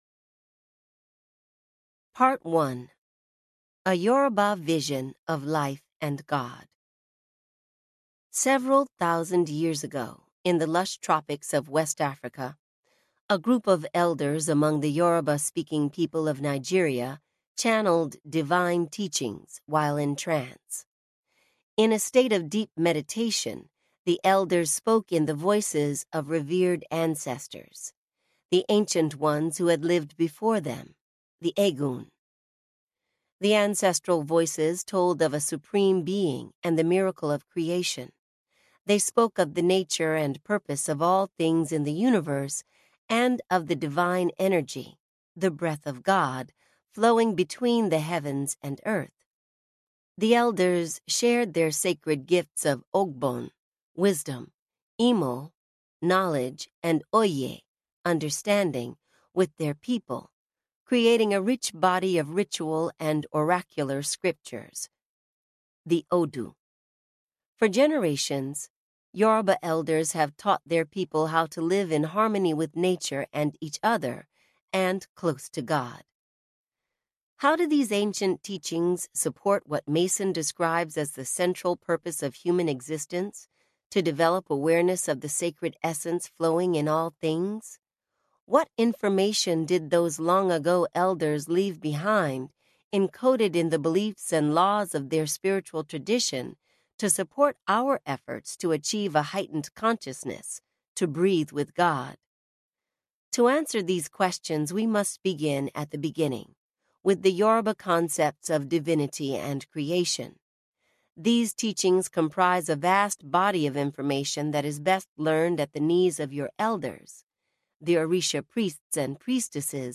Finding Soul on the Path of Orisa Audiobook
Narrator
5.3 Hrs. – Unabridged